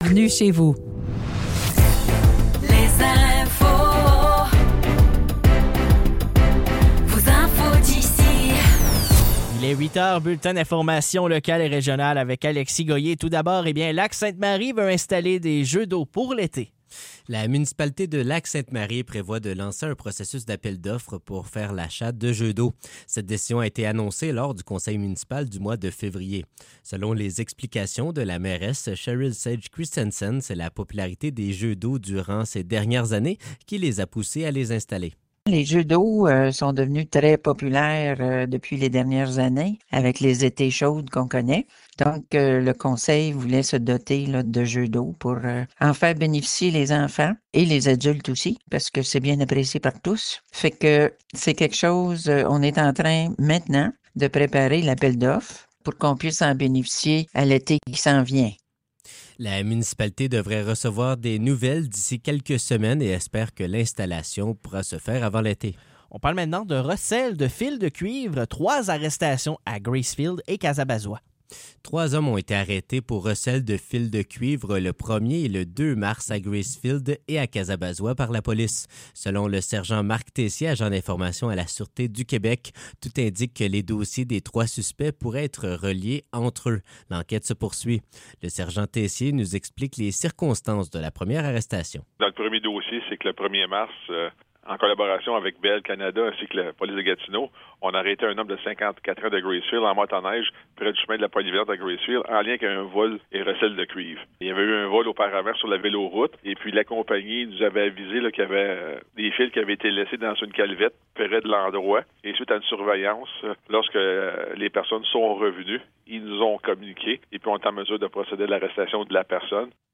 Nouvelles locales - 6 mars 2025 - 8 h